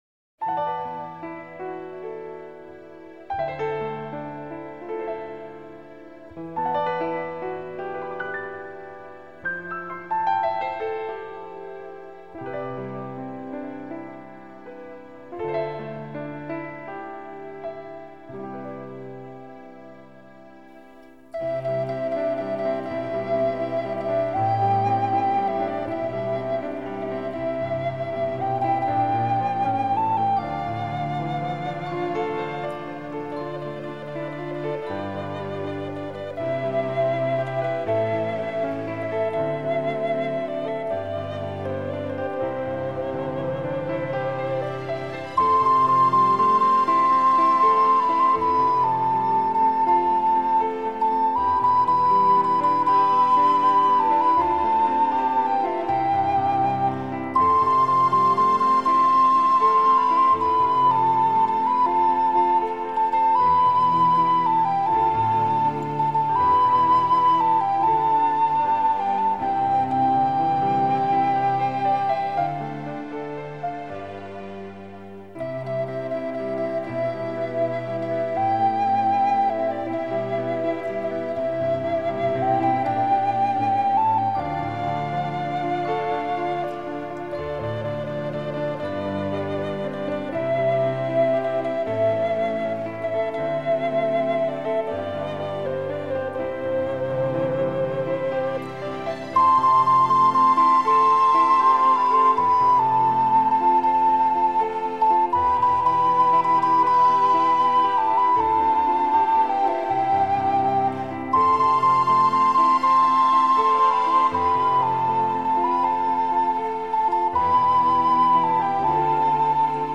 Genre: New Age.